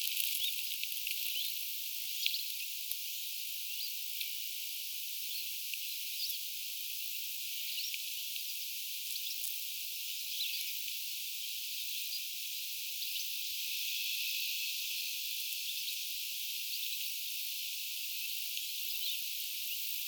Niiden ääntely on hentoa.
nuoret tiklit ääntelevät
ruokailupaikan vierellä
ilm_nuoret_tiklit_aantelevat_ruokailupaikan_puissa.mp3